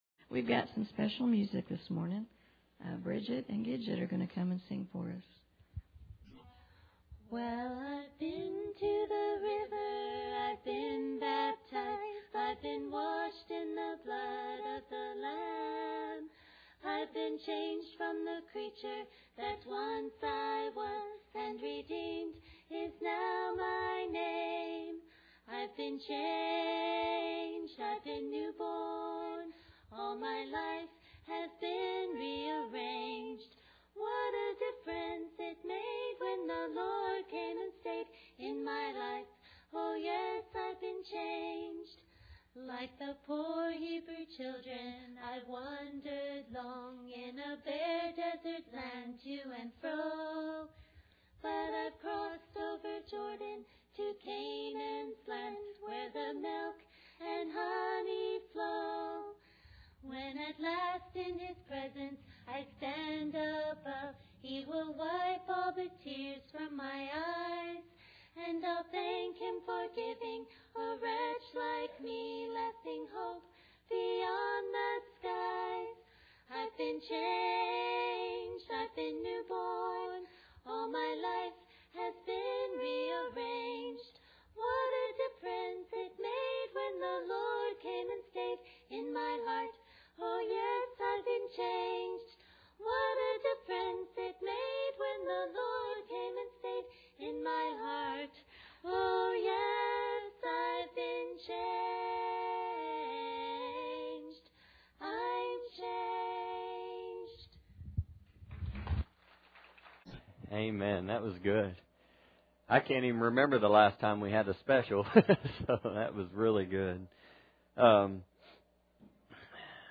Proverbs 13:21 Service Type: Sunday Morning Bible Text